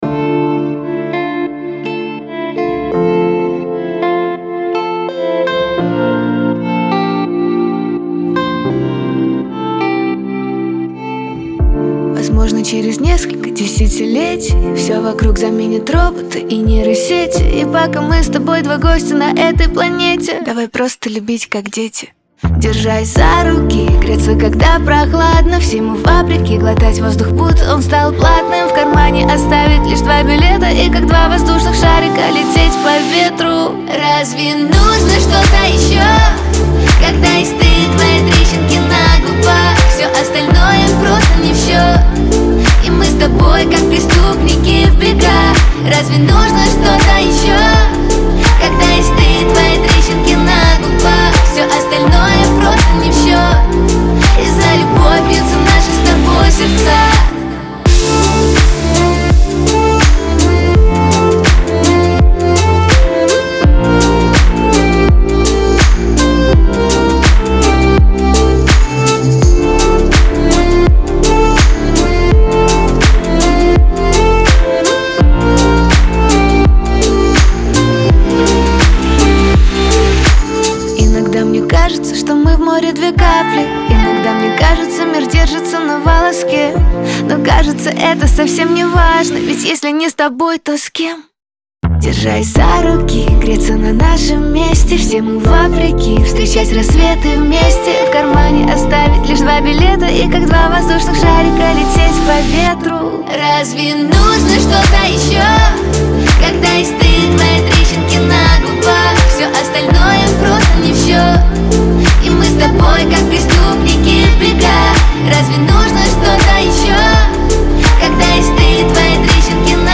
эстрада , pop , диско